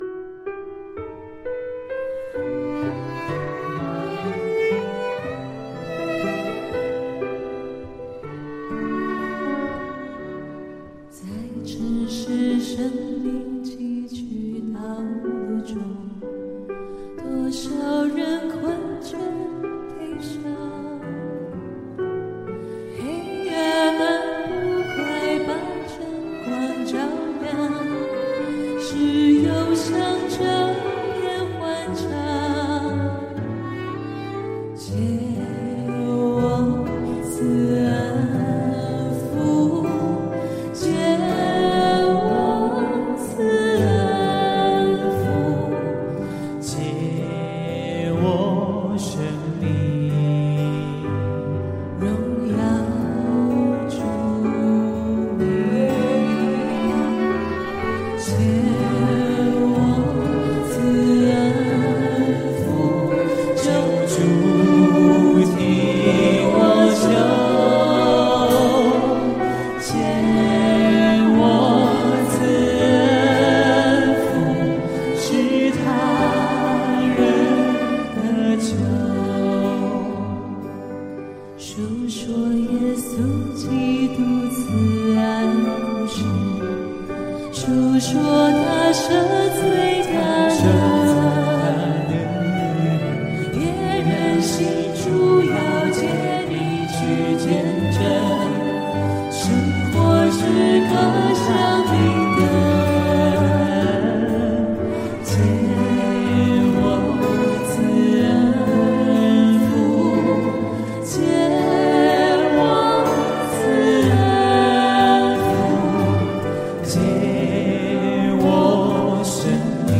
赞美诗 | 藉我赐恩福